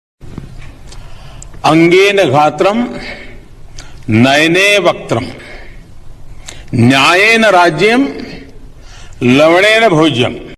modi ji bhojyam Meme Sound Effect